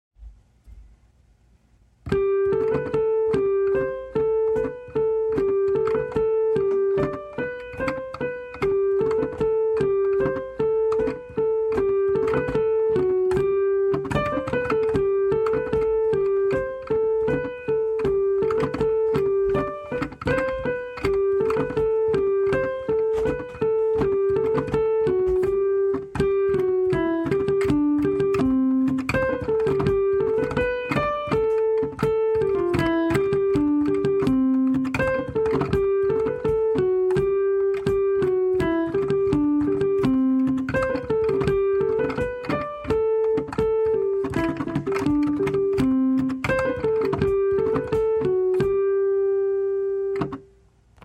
In late Summer 2019 a marchy Englishy tune was bouncing around my head.